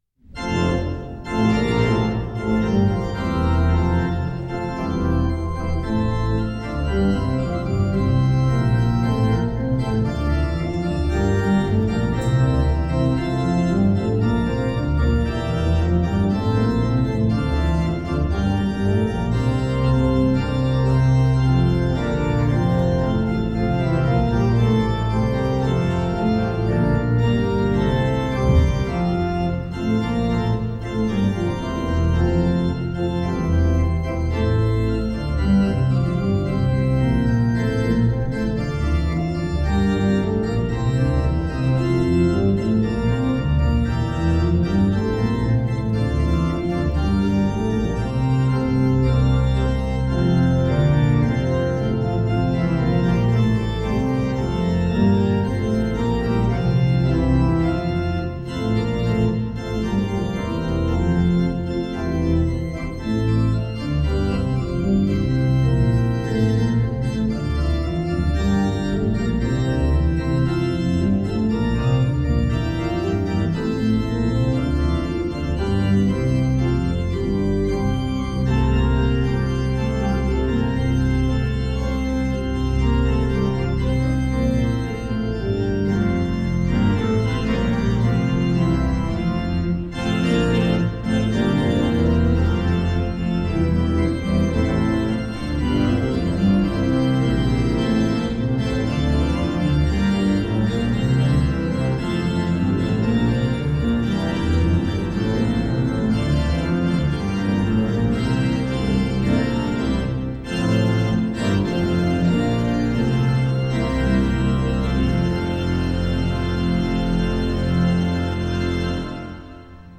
Hear the Bible Study from St. Paul's Lutheran Church in Des Peres, MO, from January 11, 2026.
Join the pastors and people of St. Paul’s Lutheran Church in Des Peres, MO, for weekly Bible study on Sunday mornings.